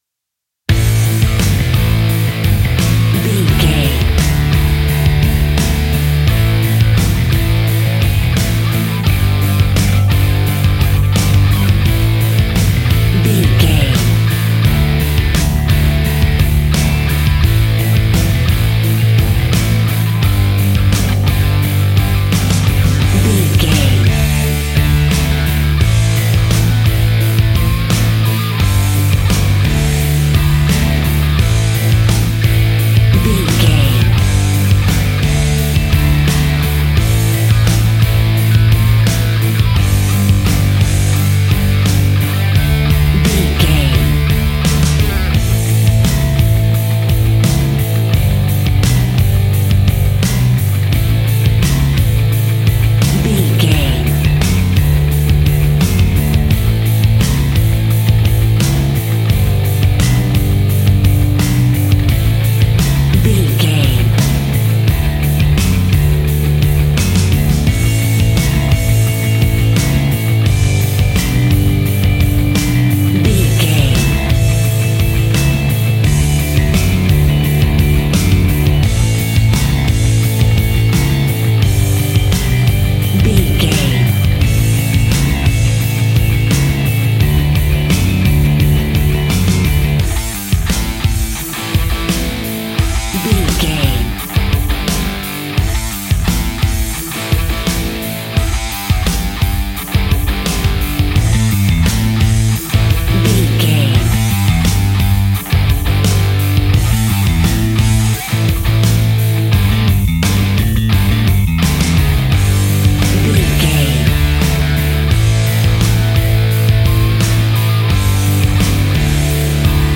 Epic / Action
Fast paced
Aeolian/Minor
hard rock
blues rock
distortion
rock instrumentals
Rock Bass
heavy drums
distorted guitars
hammond organ